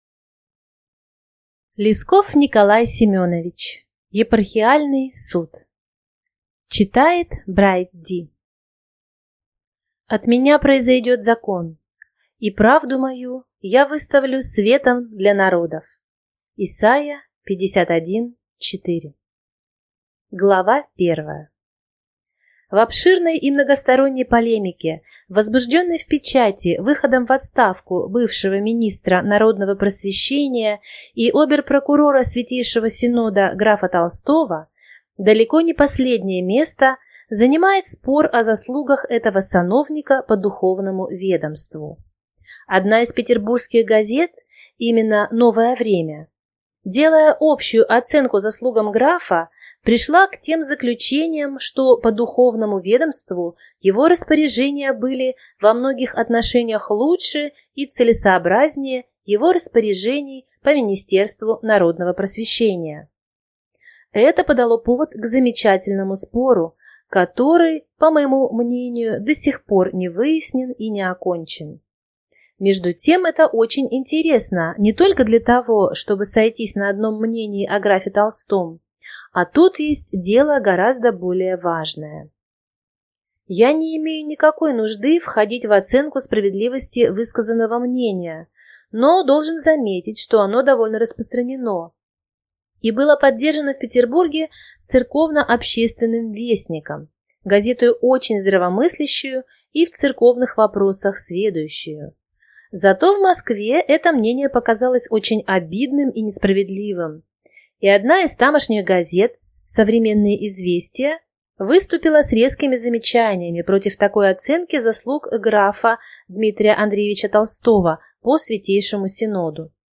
Аудиокнига Епархиальный суд | Библиотека аудиокниг